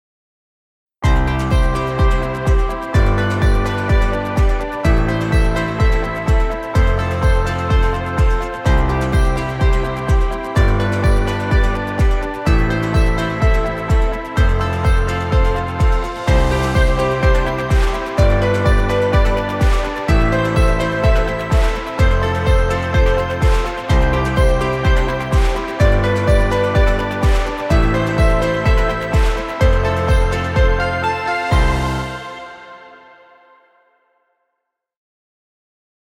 inspirational corporate track.